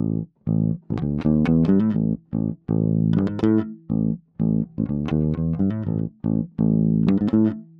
08 Bass PT1.wav